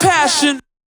007_hmah_vocalshot_67_d.wav